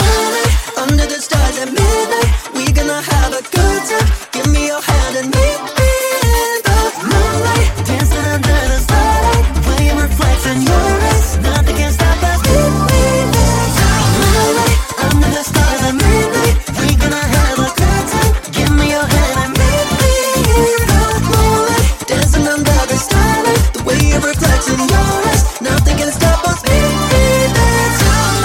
Genere: pop, slap, deep, house, edm, remix